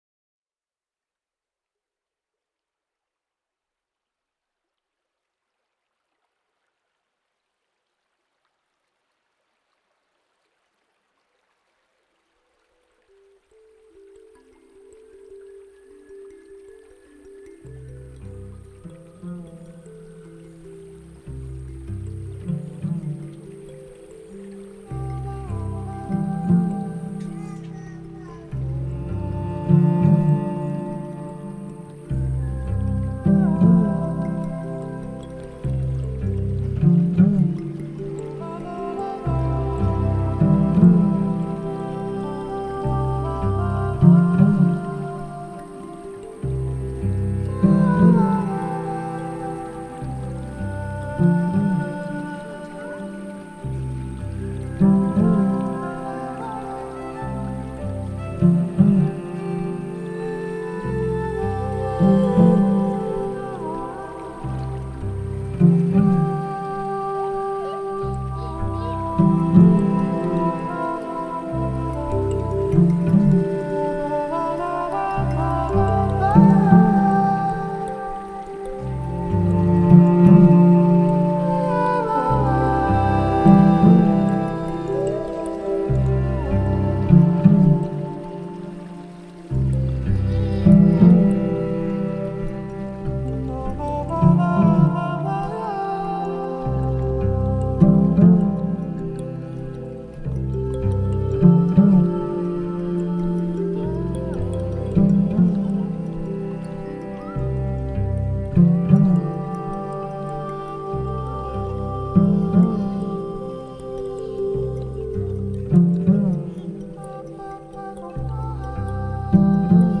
Нью эйдж New age Музыка релакс